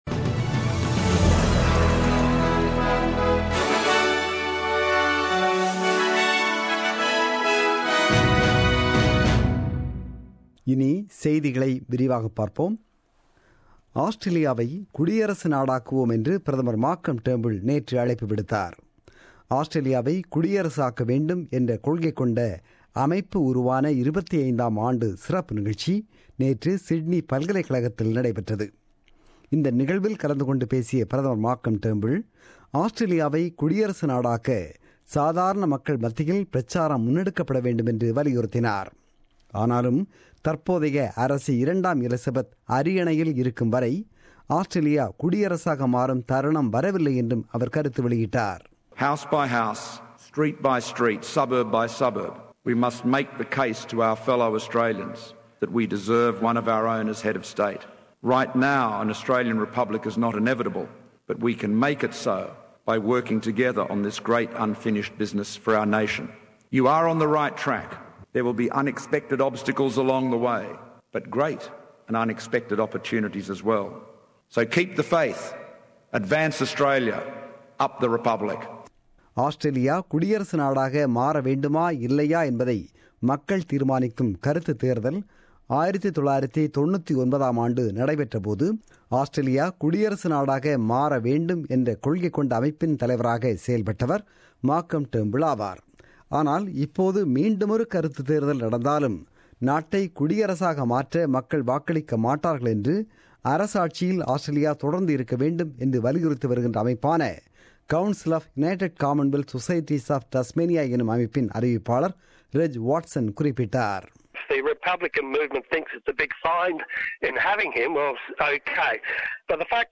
The news bulletin broadcasted on 18 December 2016 at 8pm.